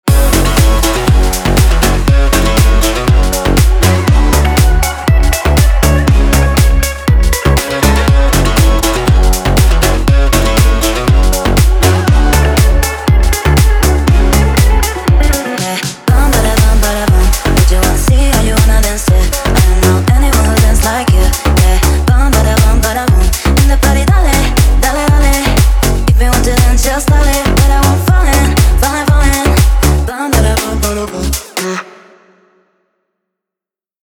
• Качество: 320, Stereo
ритмичные
громкие
зажигательные
Club House
мощные басы
электрогитара
бас-гитара